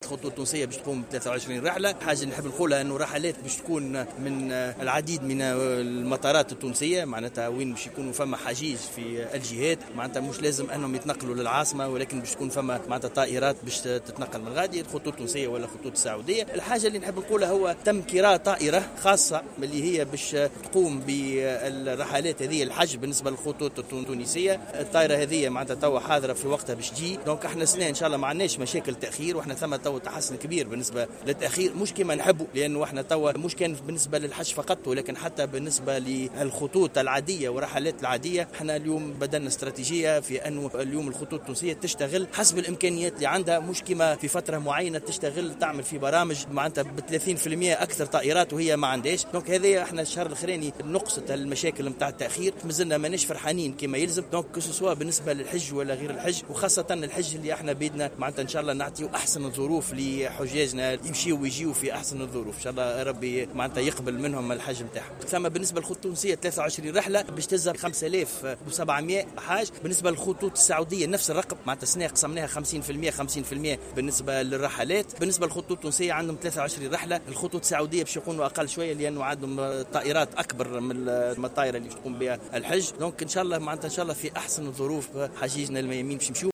قال وزير النقل هشام بن أحمد في تصريح لمراسلة الجوهرة "اف ام" إن الخطوط التونسية والسعودية ستقوم بتأمين 23 رحلة نقل حجيج من جميع المطارات التونسية نحو المملكة العربية السعودية.